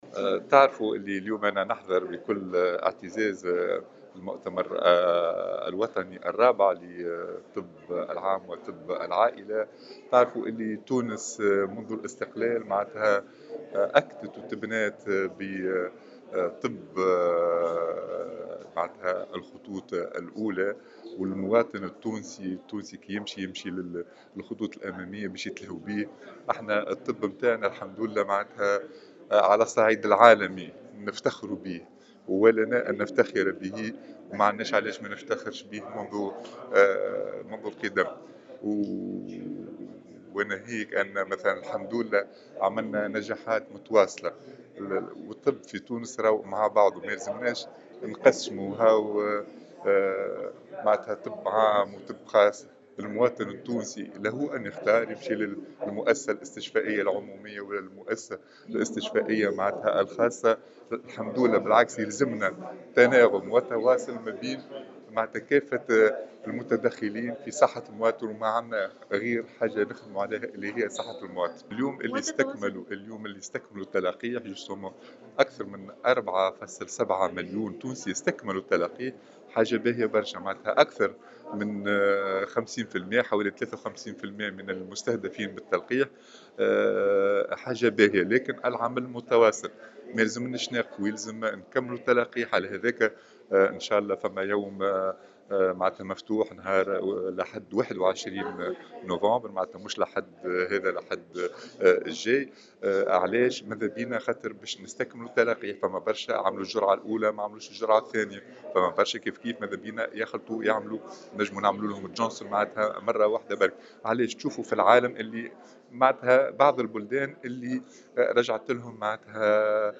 وشدد على هامش حضوره المؤتمر الوطني الرابع الذي تنظمه الجمعية التونسية للطبّ العام وطب العائلة، على ضرورة مواصلة استكمال التلاقيح لمَنْ تخلفوا عن ذلك، مُعلنا انه سيتّم تنظيم يوم مفتوح للعموم يوم 21 نوفمبر 2021 وفتح (400 مركز) وذلك في إطار مزيد الدفع نحو اكتساب المناعة الجماعية خاصة وان بعض البلدان في الخارج تشهد عودة موجة جديدة من فيروس كورونا بسبب عدم استكمالها للتلاقيح وفق قوله.